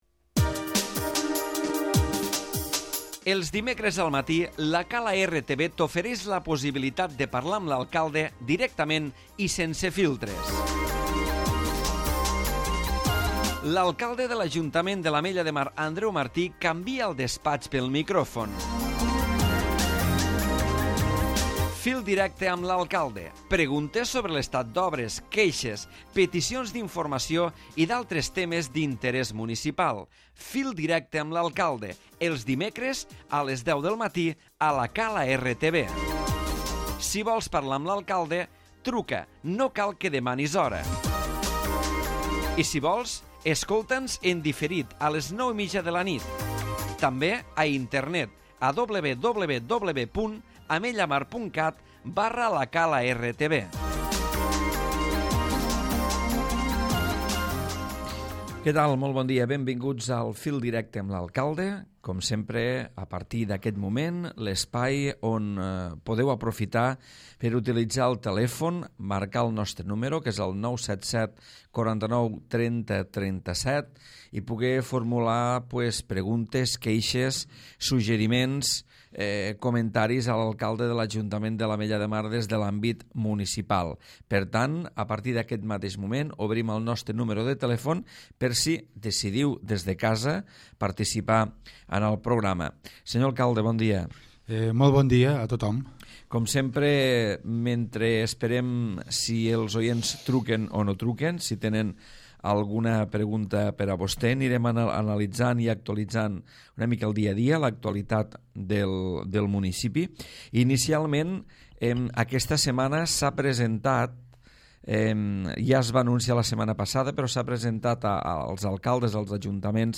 Programa de participació ciutadana amb trucades dels oients i repàs a l'actualitat municipal a càrrec de l'Alcalde Andreu Martí.